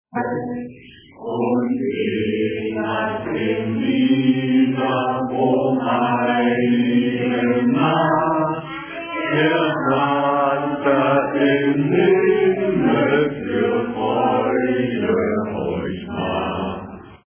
"Lebendiger Adventskalender" Hainholz